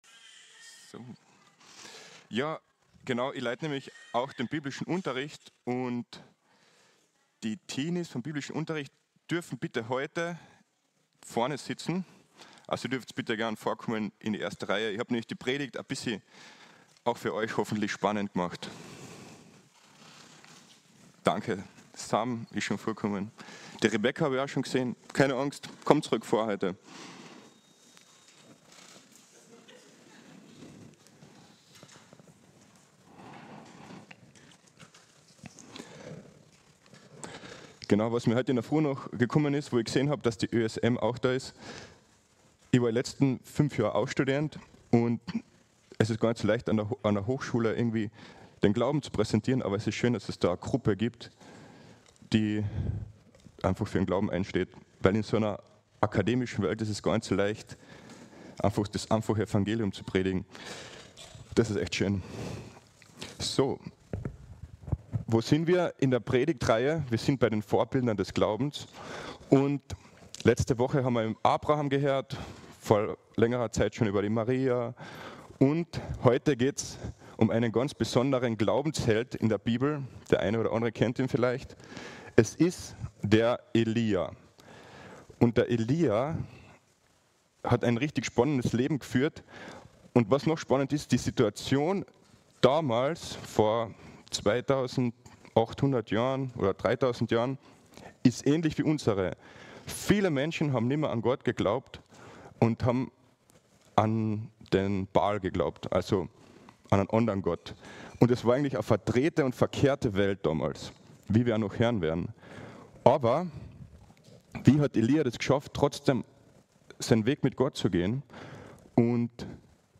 Aus der Serie: "Einzelpredigten 2024"